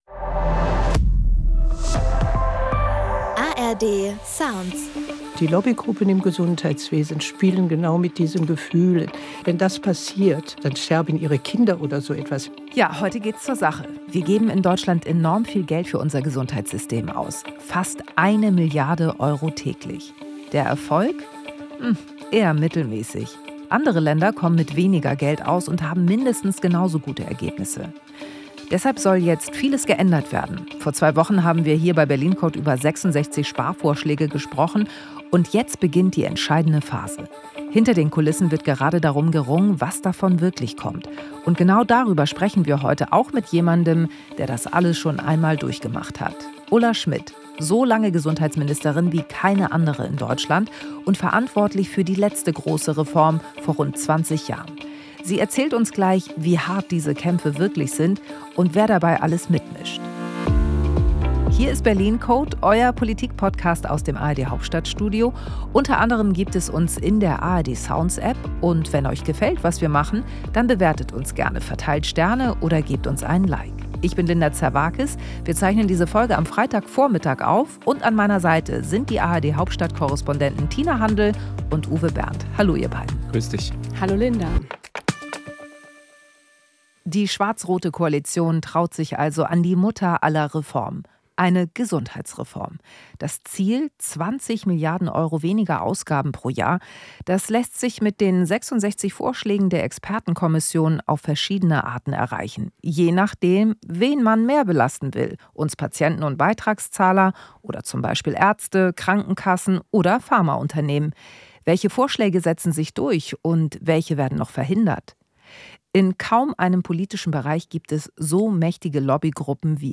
In einem Interview erzählt uns die ehemalige Gesundheitsministerin Ulla Schmidt, wie die Interessenvertreter arbeiten und wer die härtesten Reformgegner sind.